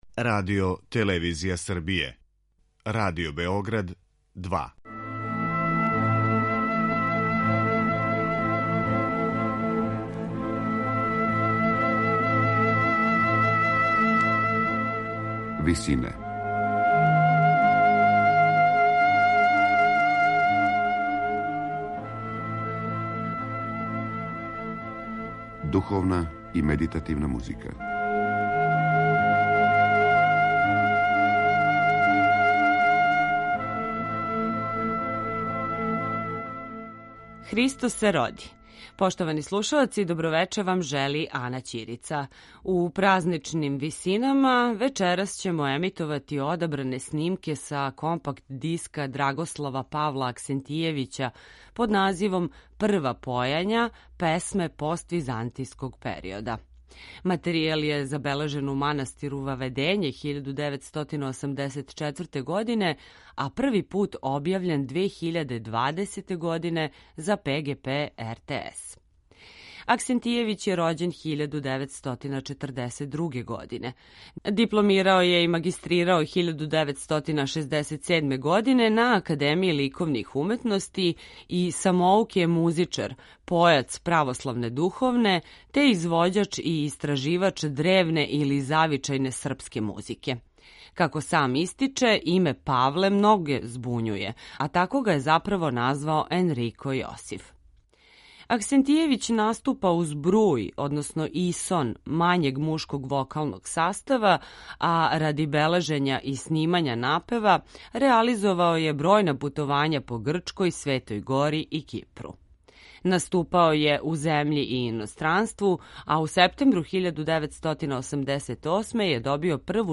Појања